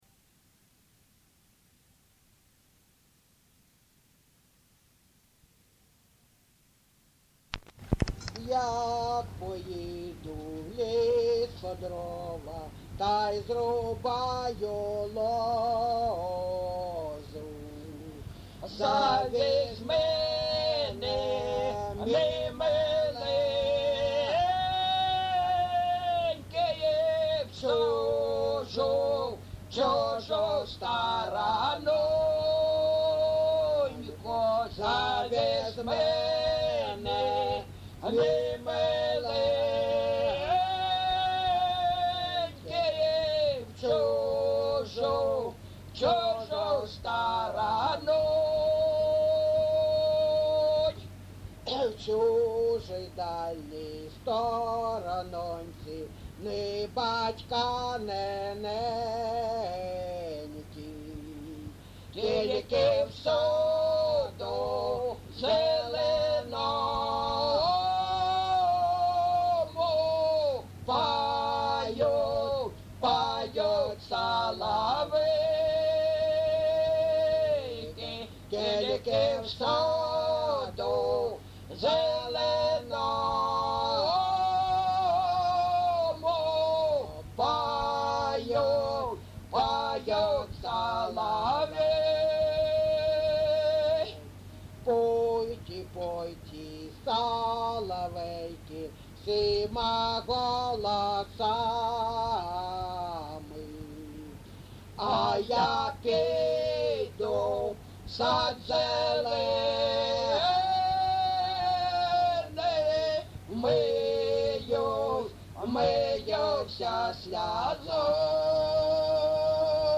ЖанрПісні з особистого та родинного життя
Місце записус. Григорівка, Артемівський (Бахмутський) район, Донецька обл., Україна, Слобожанщина